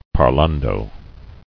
[par·lan·do]